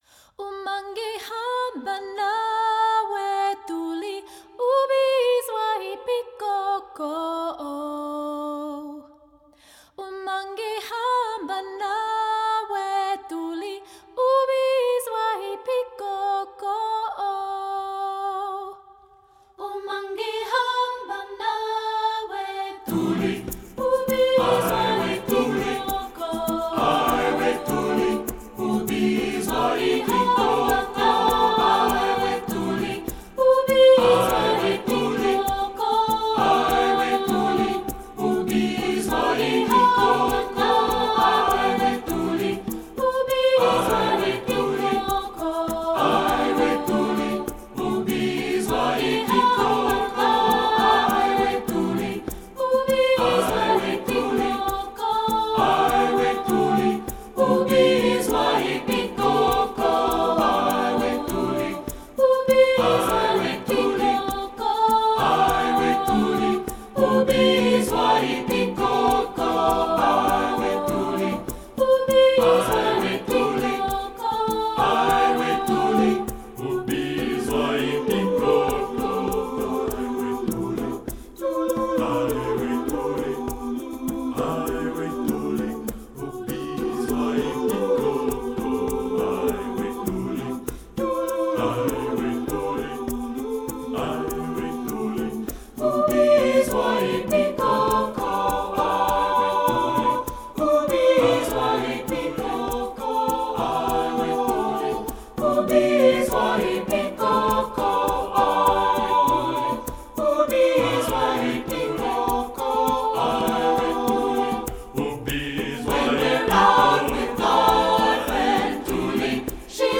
Composer: South African Folk Song
Voicing: SATB a cappella